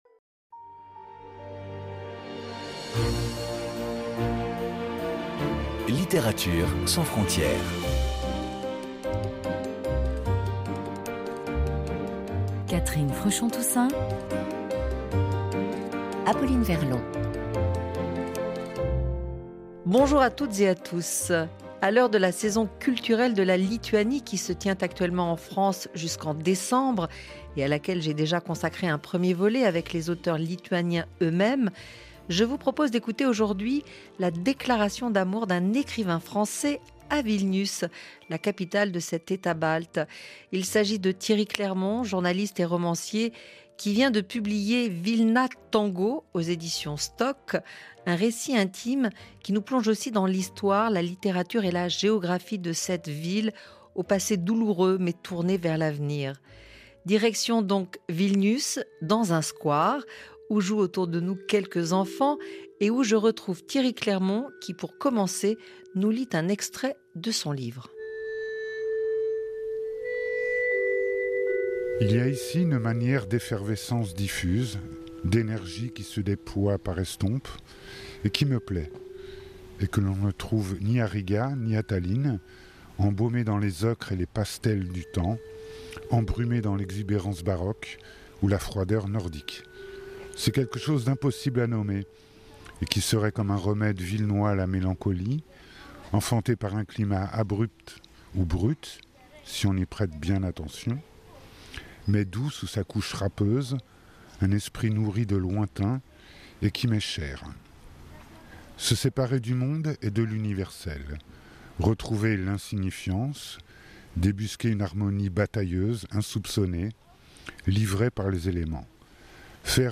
Un entretien exceptionnel donc où, pour la dernière fois, il parle de ses livres.